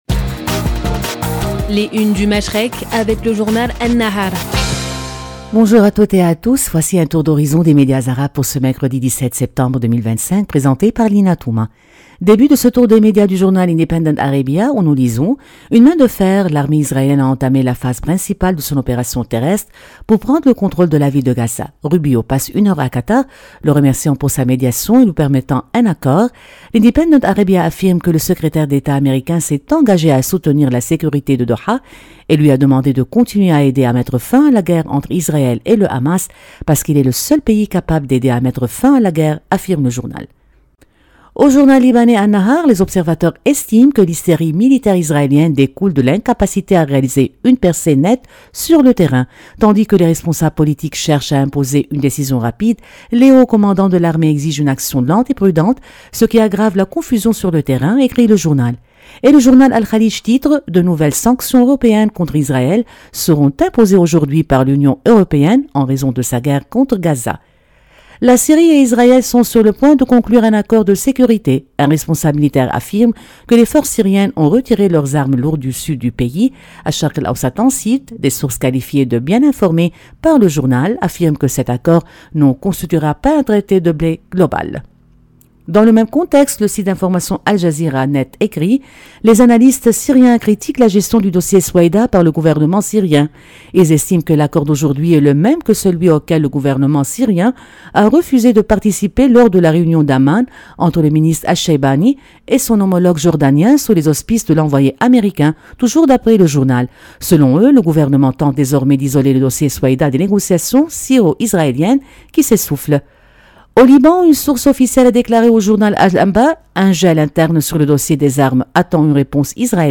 Revue de presse des médias arabes du 17 septembre 2025